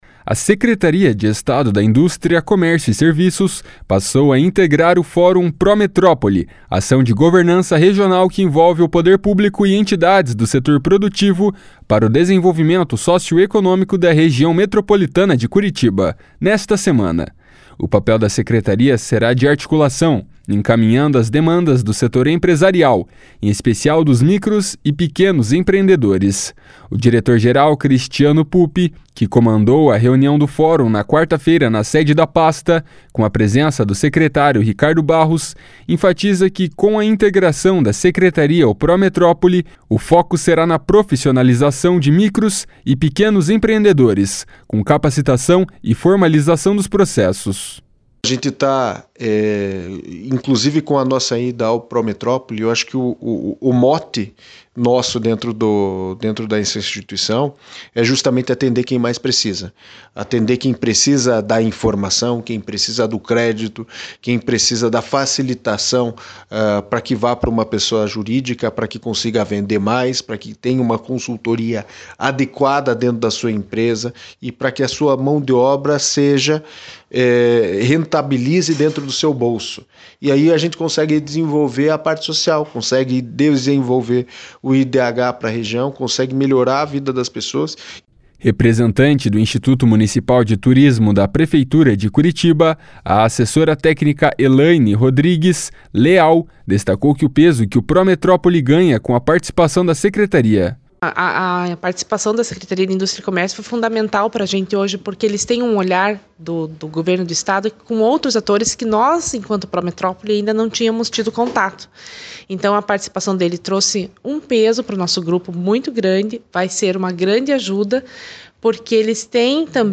No Estado, o Pró-Metrópole já tem uma aproximação grande com a Secretaria de Cidades, responsável pelos investimentos urbanos, e a Agência de Assuntos Metropolitanos, que inclusive conduz o Plano de Desenvolvimento Urbano Integrado da Região Metropolitana de Curitiba. (Repórter